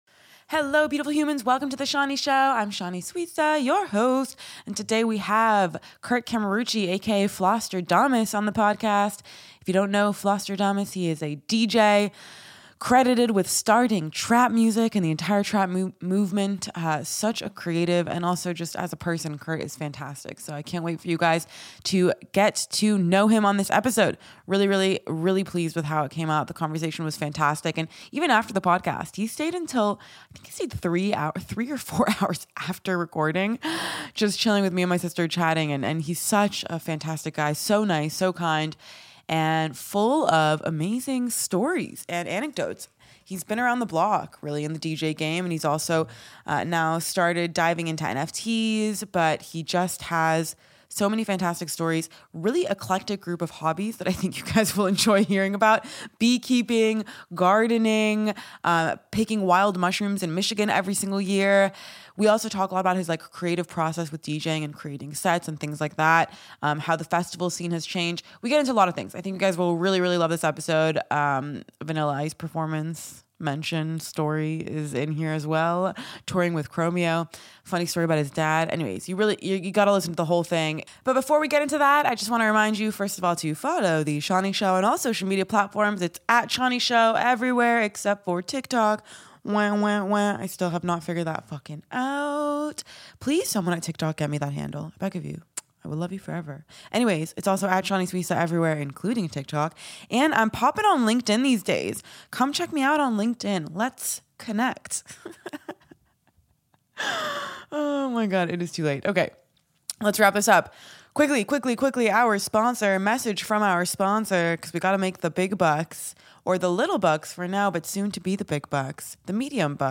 Tune in every week for sharp takes and interviews.